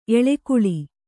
♪ eḷekuḷi